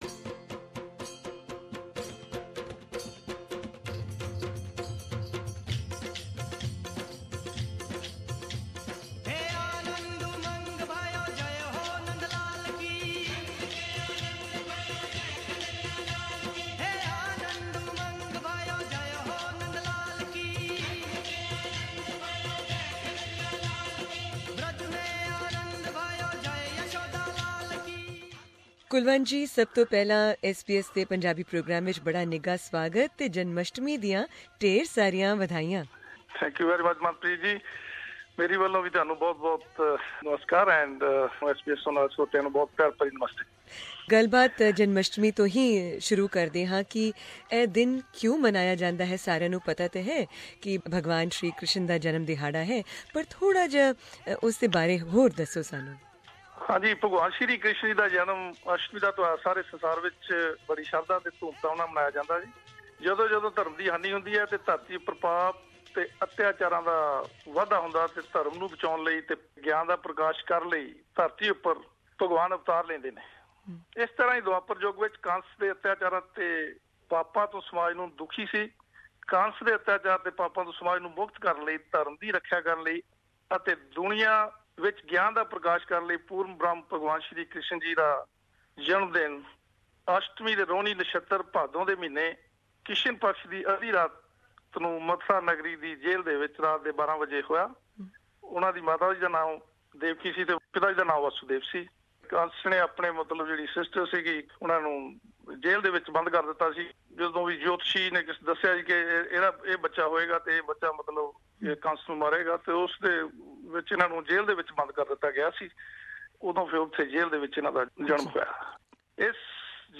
SBS Punjabi